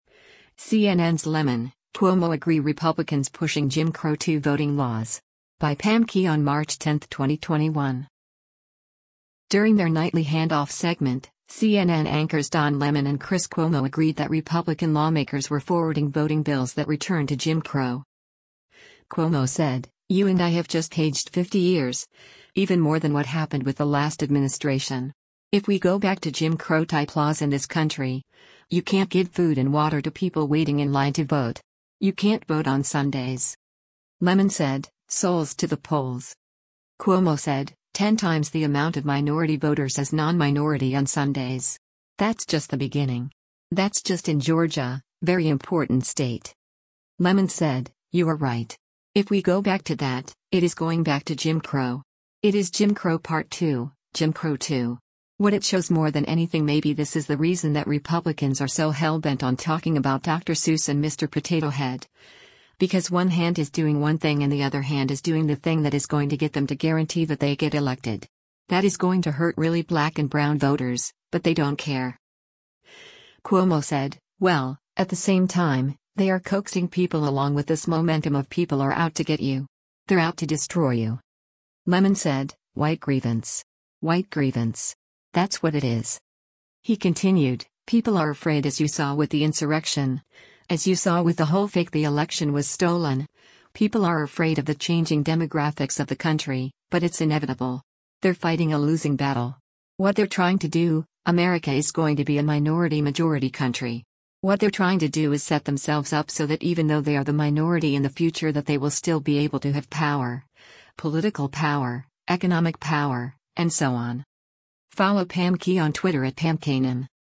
During their nightly handoff segment, CNN anchors Don Lemon and Chris Cuomo agreed that Republican lawmakers were forwarding voting bills that return to “Jim Crow.”